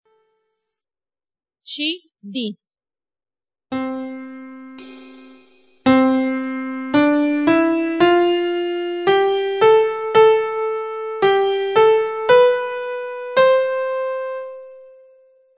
Qualora gli mp3 (di 2ª qualità per non appesantire il sito), di questa pagina, non fossero perfetti nell'ascolto, scriveteci, Vi invieremo sulla vostra casella di posta i file di 1ª qualità, gratuitamente.